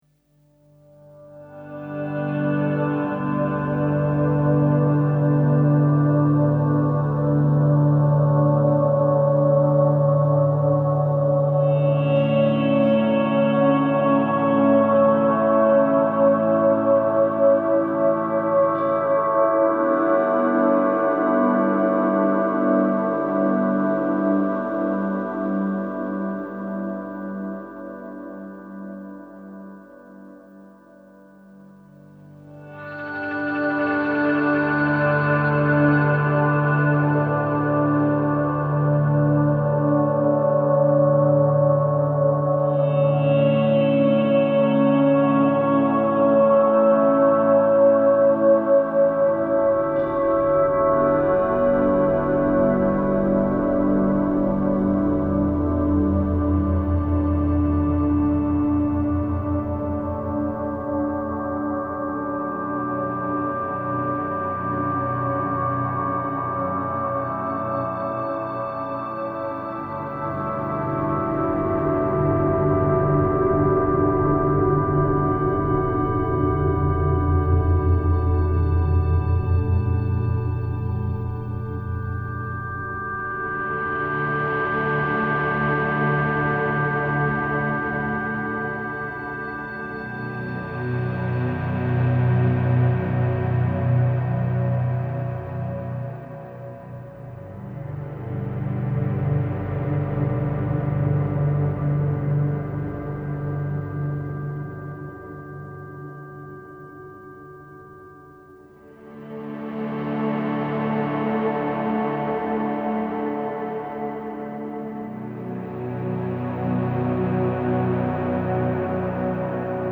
who are perhaps my favourite ambient outfit.